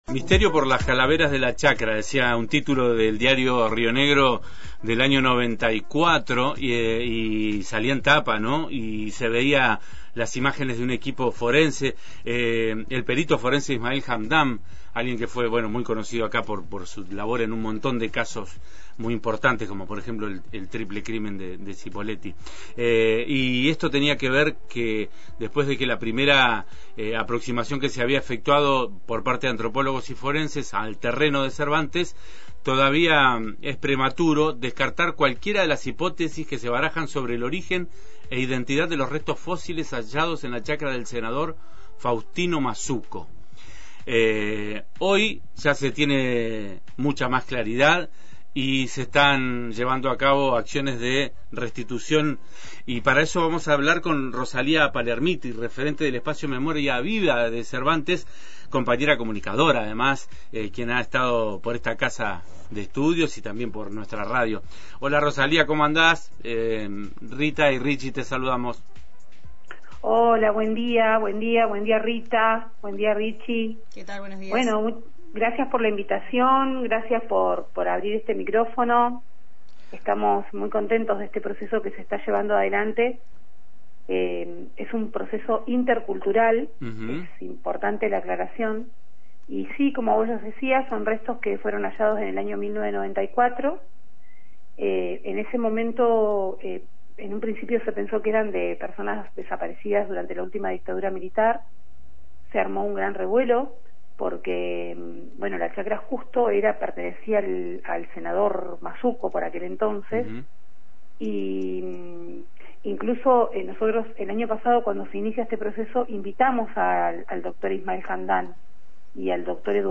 En diálogo con Antena Libre,